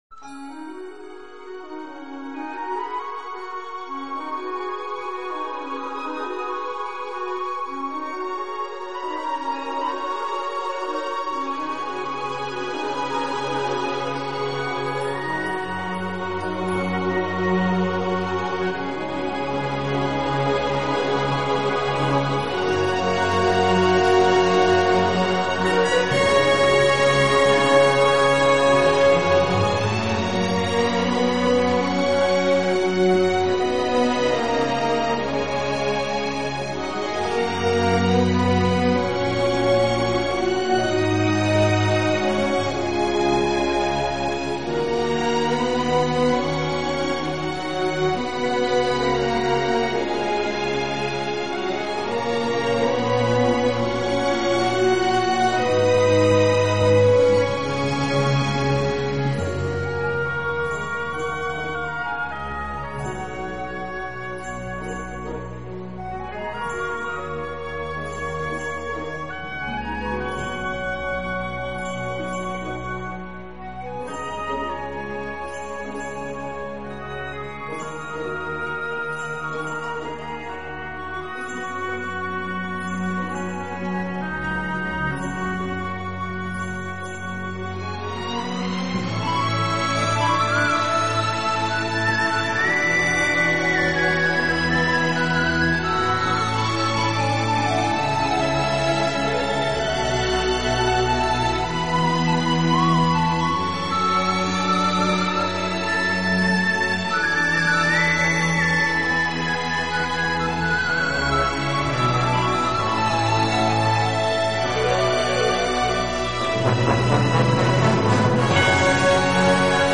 音乐类型：New  Age
这种音乐是私密的，轻柔的，充满庄严感并总是令人心